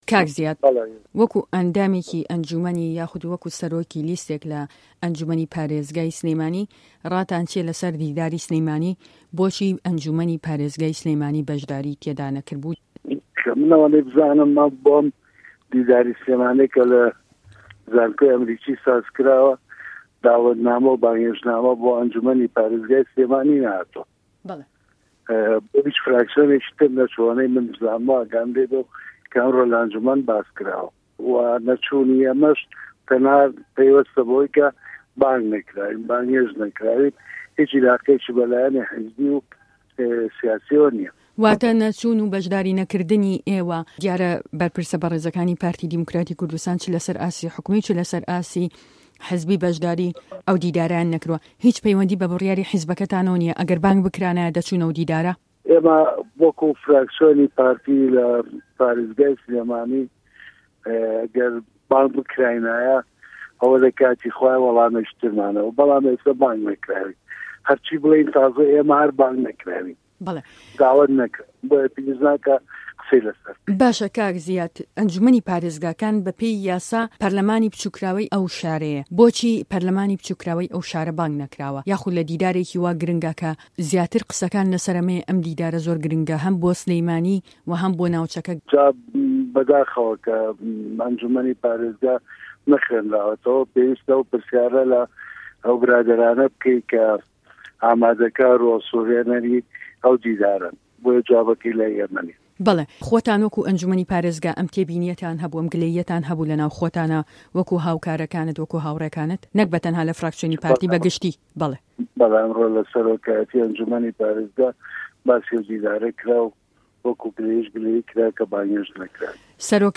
وتووێژه‌كه‌ی